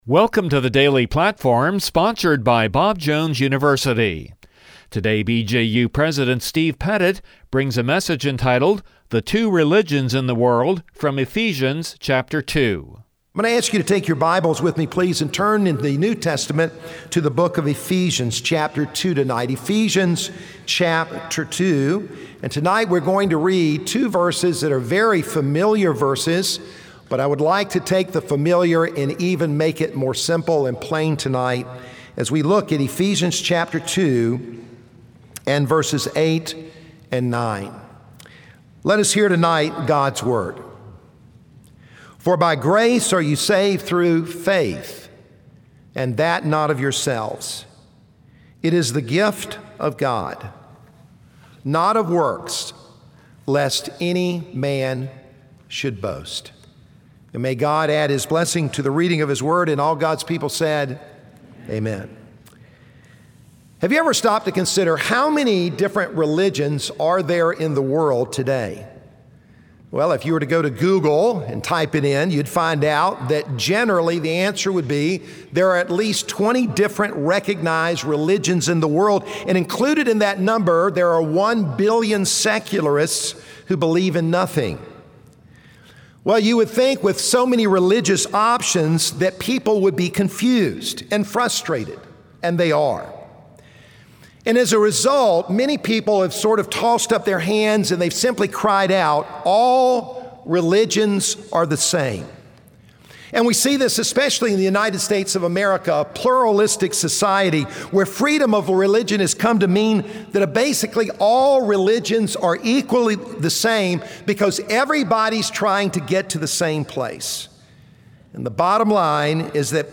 From the chapel service on 08/28/2018